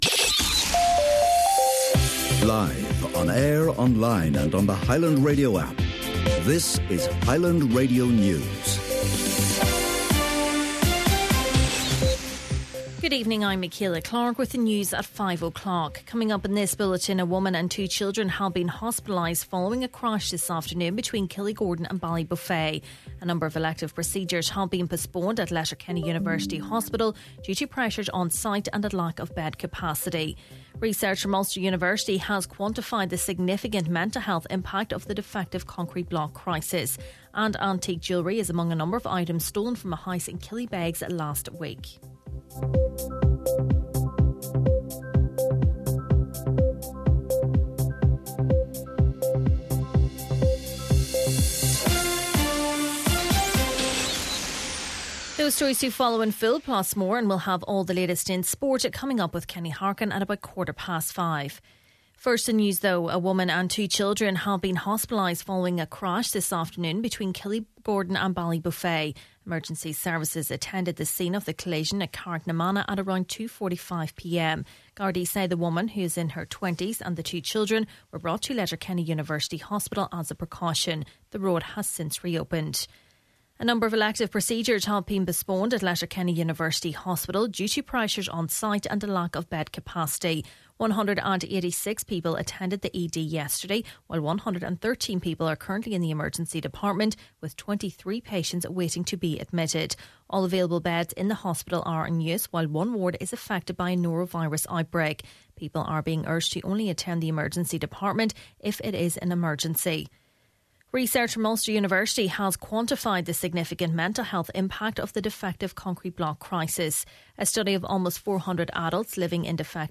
Main Evening News, Sport and Obituaries – Tuesday, March 11th